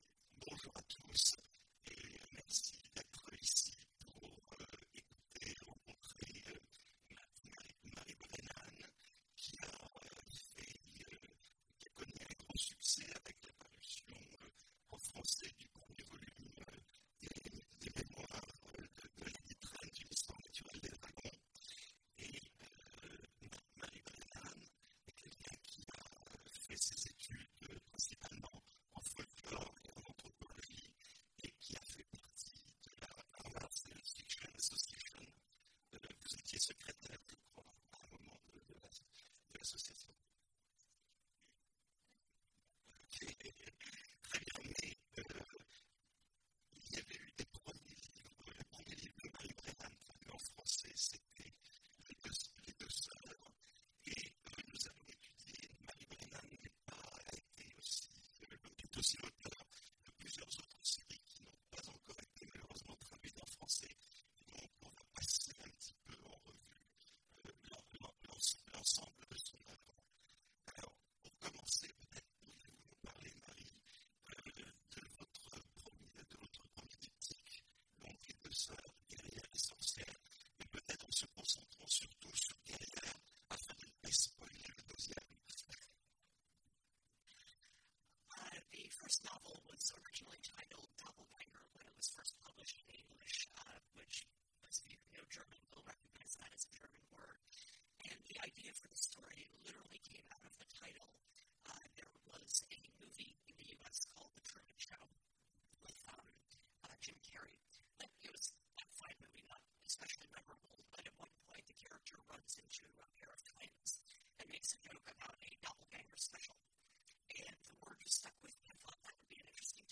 Imaginales 2016 : Entretien avec… Marie Brennan
Marie Brennan Télécharger le MP3 à lire aussi Marie Brennan Genres / Mots-clés Rencontre avec un auteur Conférence Partager cet article